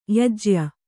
♪ yajya